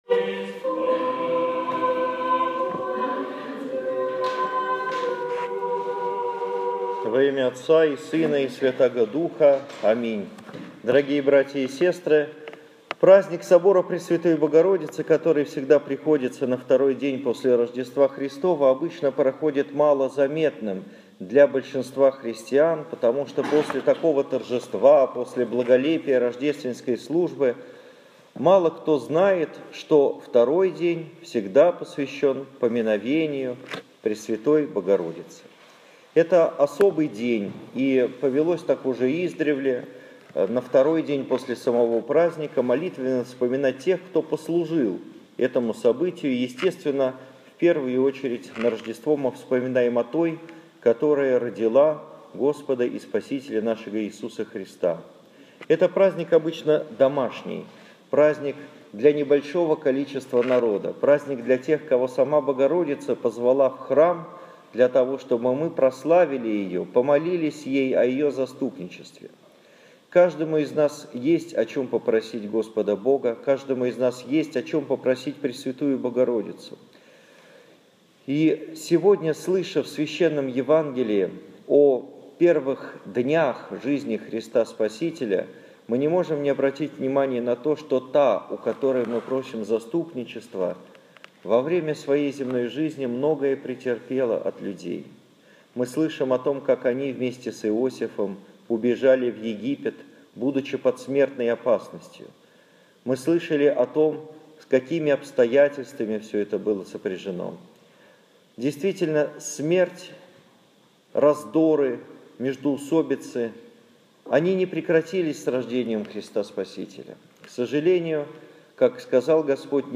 Моя проповедь в Собор Пресвятой Богородицы, 8 января 2015 года, в нашем Петропавловском храме (Шуваловский парк, Парголово, Спб) после Евангелия на литургии.
Домой / Проповеди / Аудио-проповеди / 8 января 2015 года.